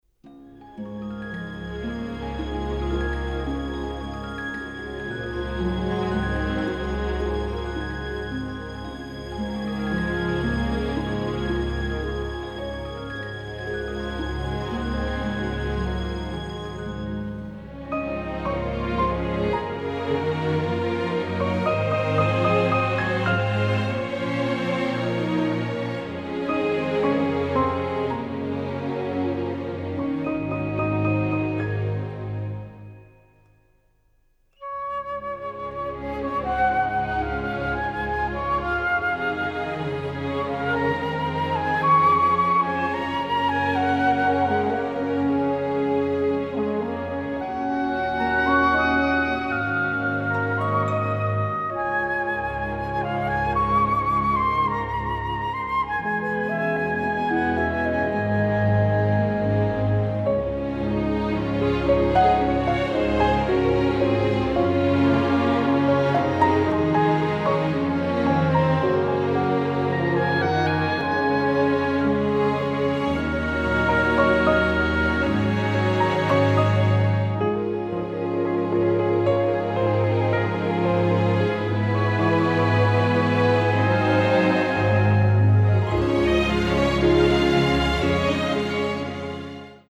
This orchestral album
A nice easy listening version of music